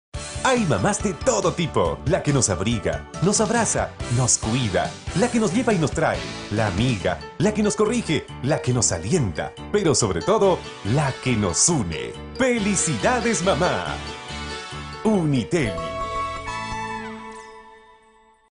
spanisch Südamerika
Kein Dialekt